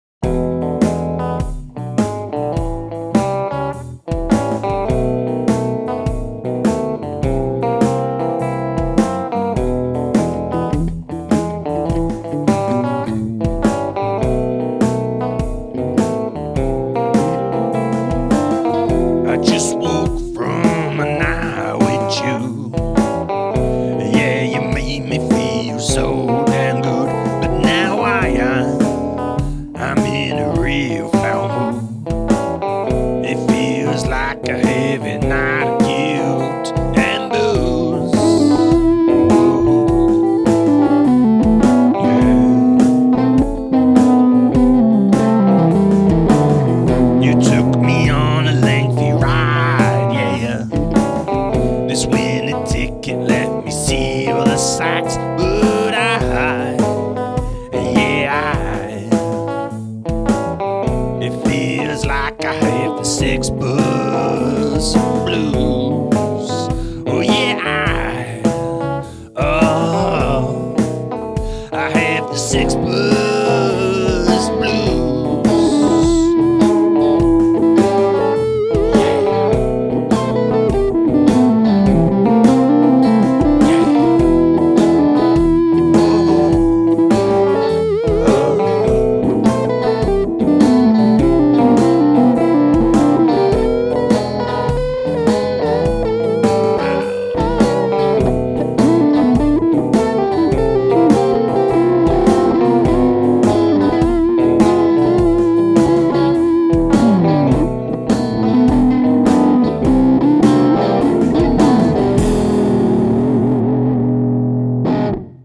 A drunken night on ones own played out in stereo.